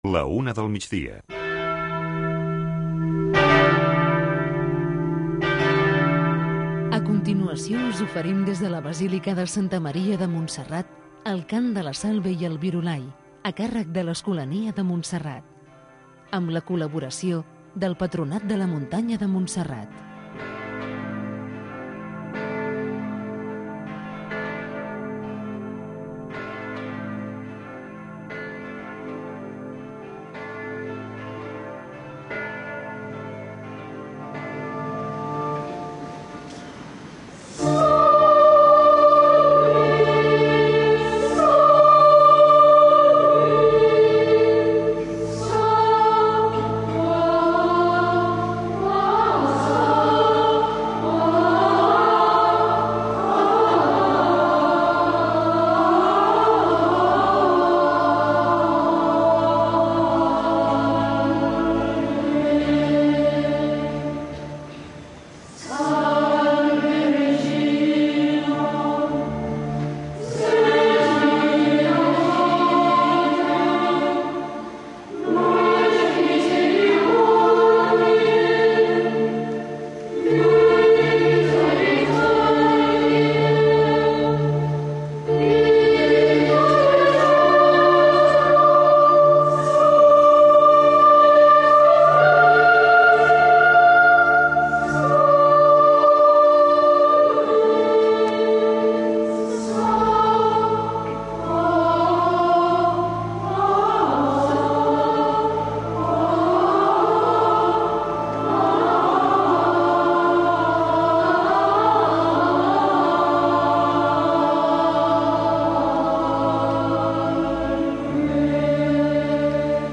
amb l'Escolania de Montserrat
L’escolania de Montserrat canta la Salve i el Virolai als peus de la Moreneta, com un moment de pregària al migdia, en el qual hi participen molts fidels, pelegrins i turistes que poden arribar omplir a vessar la basílica.